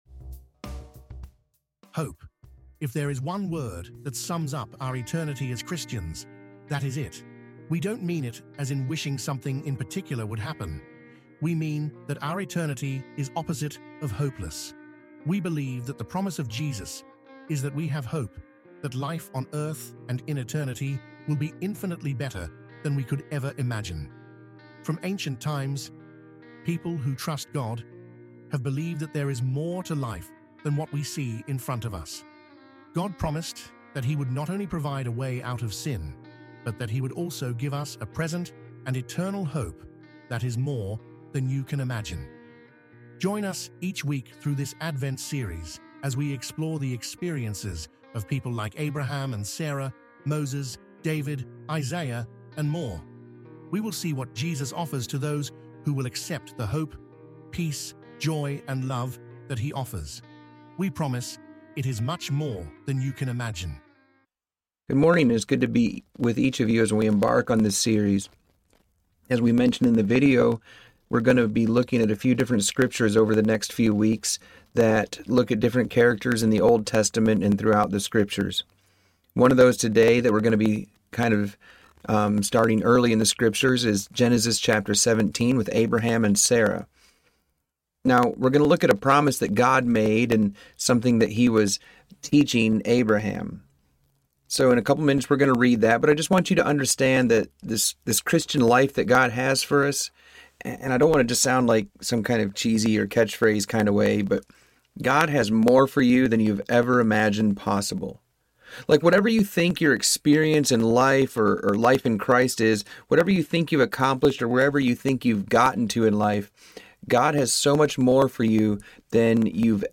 In the sermon, Pastor delves into the story of Abraham and Sarah as outlined in Genesis 17. He emphasizes God’s promises to them, highlighting the covenant and profound significance of faith.